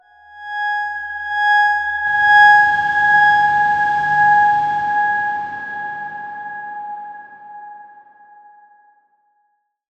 X_Darkswarm-G#5-mf.wav